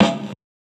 SNARE 90S 7.wav